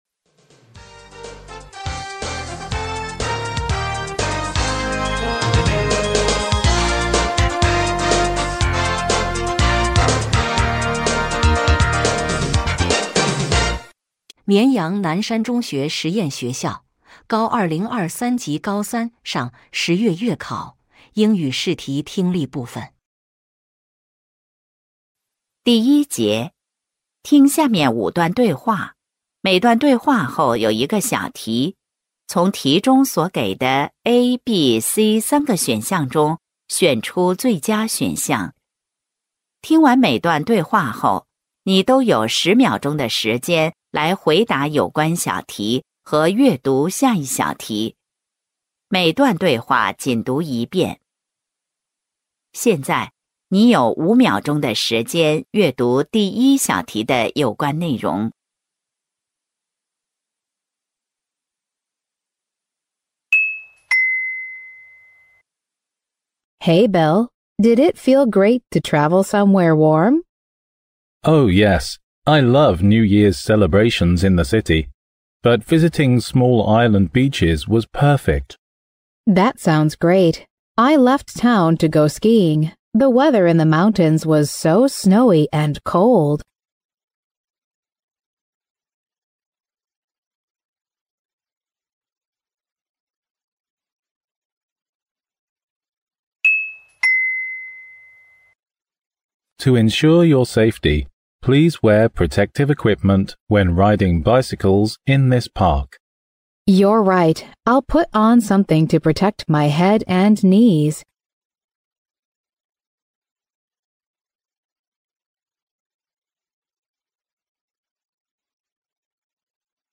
绵阳南山中学实验学校高2023级高三（上）10月月考英语听力.mp3